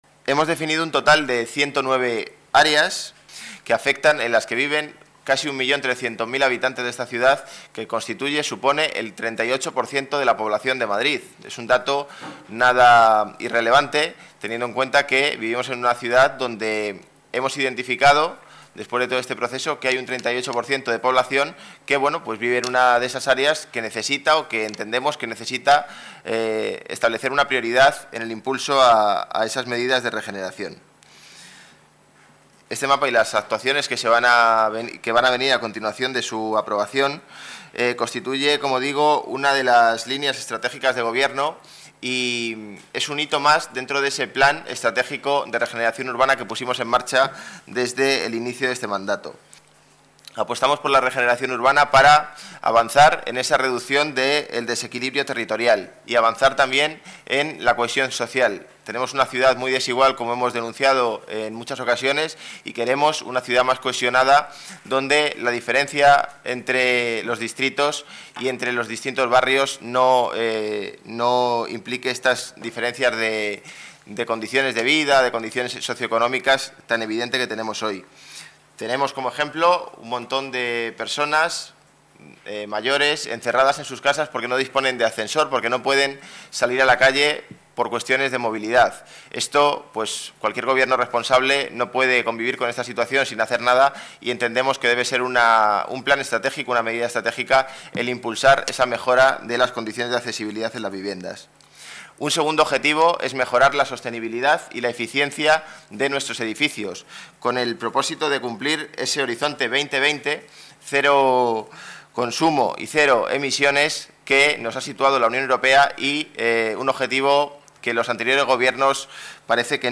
Nueva ventana:Calvo explica los mecanismos de cara a las Comunidades de Vecinos
Calvo explica los mecanismos de cara a las Comunidades de Vecinos El concejal de Desarrollo Urbano Sostenible explica las áreas en las que se intervendrá José Manuel Calvo explica cómo el plan de regeneración también creará puestos de trabajo